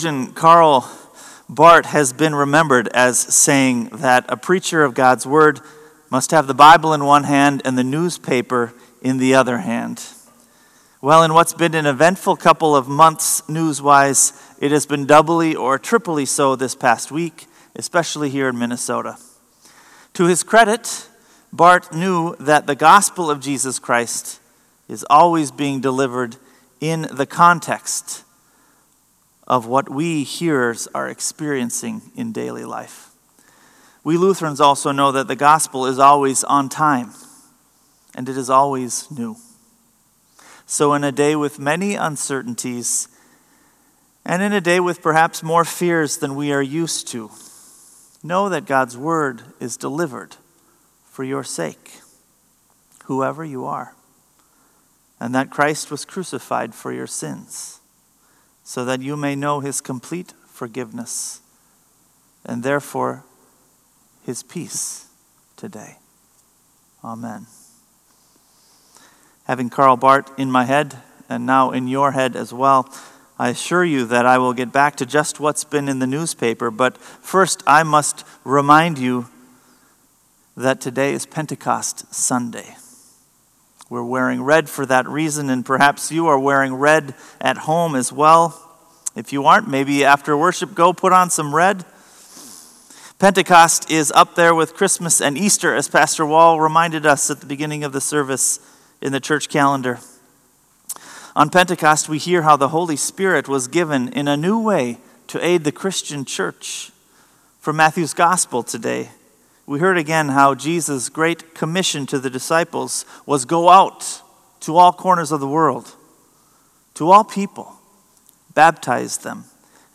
Sermon “More Than Conquerors”